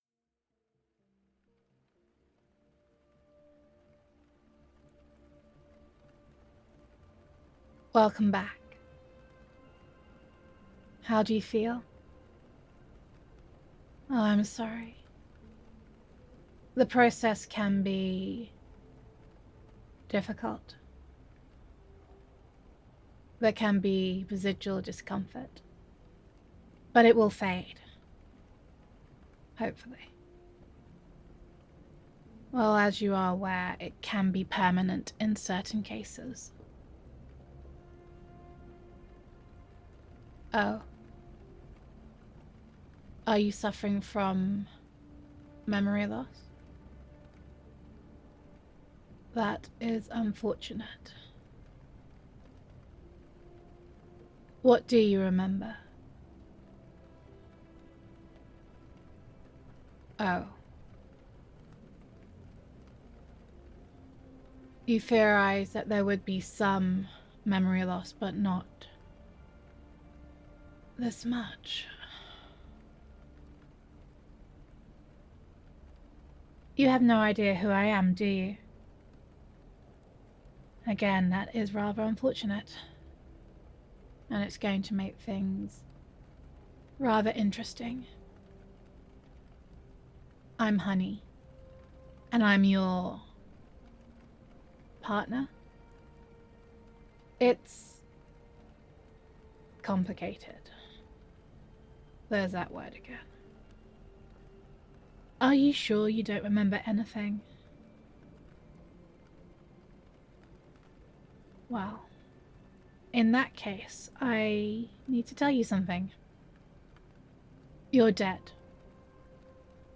[F4A] Contingency [Welcome Back][Reports of Your Death Have Been Greatly Exaggerated][You Were Murdered][Detective!Listener][You Need to Find out Who Murdered You][Amnesia][Gender Neutral][Cyberpunk AI Roleplay]